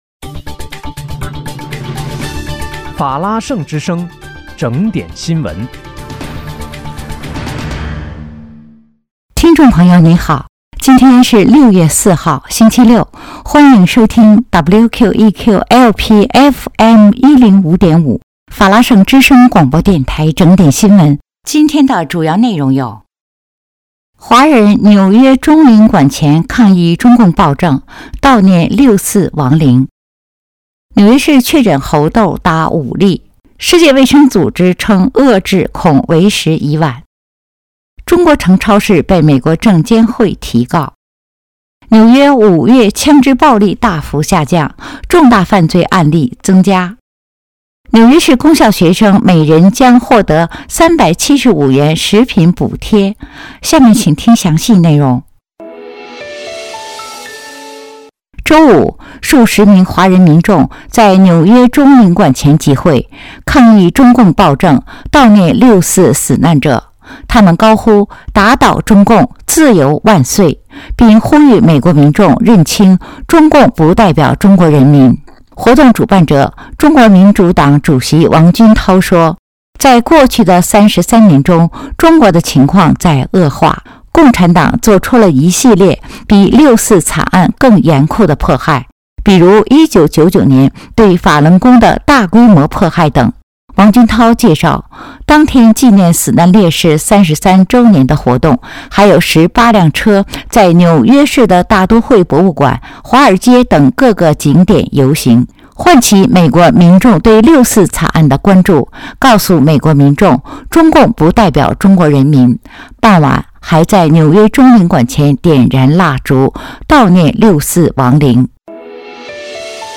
6月4日（星期六）纽约整点新闻